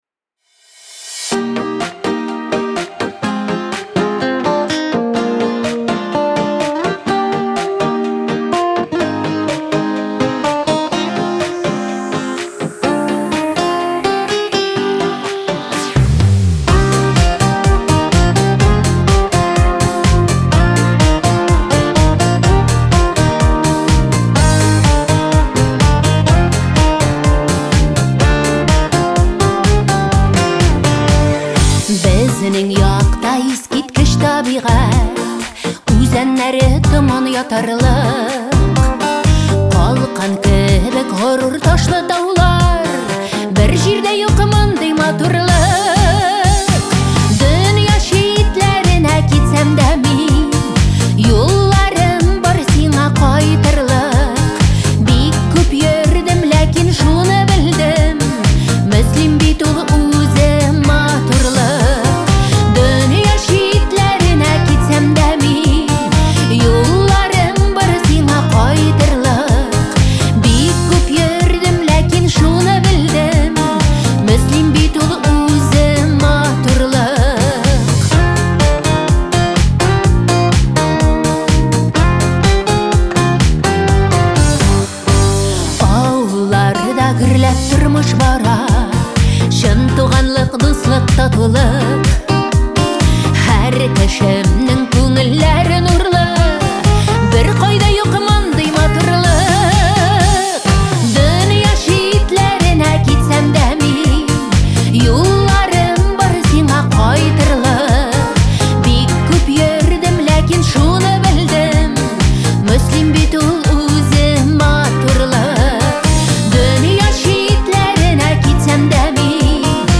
Ул илһамландыра, җанлы һәм үзенчәлекле тәэсир калдыра.